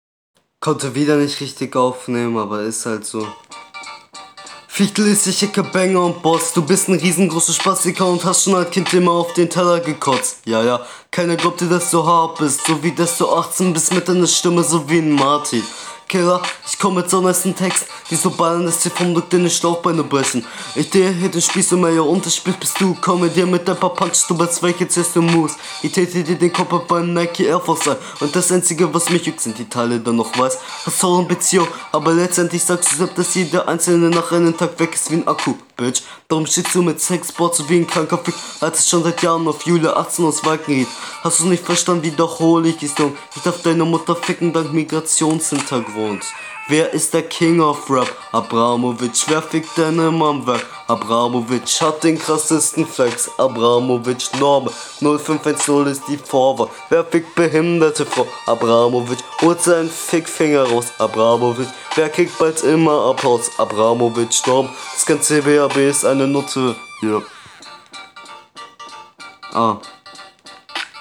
Flow: nicht so gut wie hr1 aber meistens im Takt.